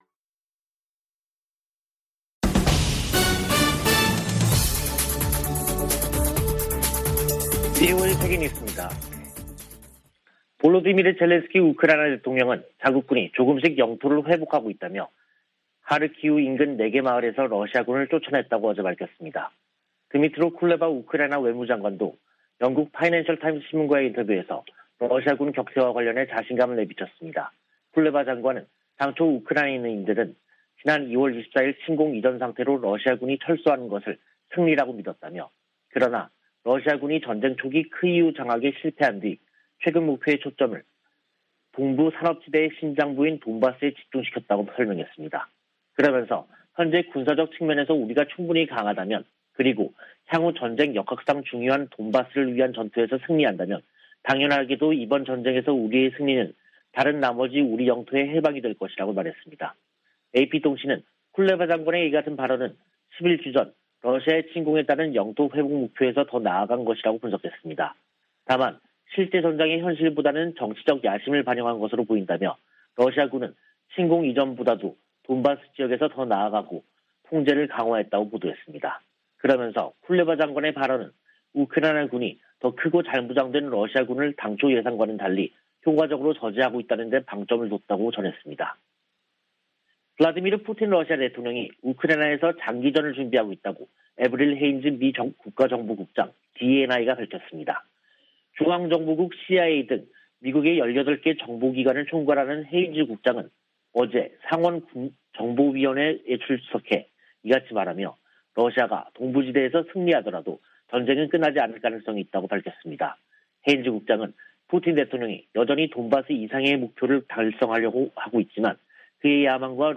VOA 한국어 간판 뉴스 프로그램 '뉴스 투데이', 2022년 5월 11일 2부 방송입니다. 윤석열 한국 신임 대통령이 취임사에서 북한의 무력시위에 대한 언급 없이 비핵화 전환을 조건으로 경제협력 의지를 밝혀 신중한 입장을 보였다는 평가가 나오고 있습니다. 미 국무부는 한국 새 정부와 긴밀히 조율해 북한의 위협에 대응할 것이라고 밝혔습니다.